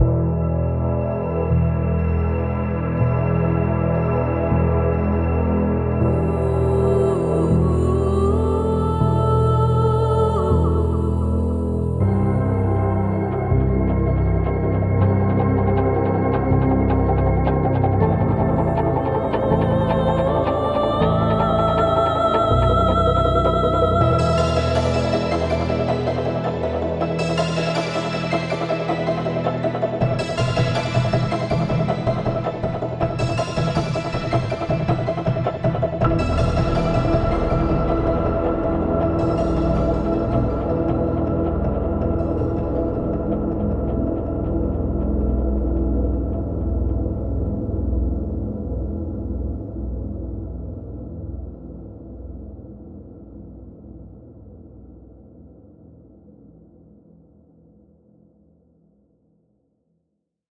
3. 氛围铺底
Impact Soundworks The 88E 是一款虚拟钢琴插件，它的特点是每个音符都调成了 E 音，并且同时演奏。
- 调制：您可以通过调节调制深度、调制速度、调制形状等参数来给钢琴添加颤音、合唱、相位等效果。